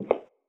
inside-step-2.wav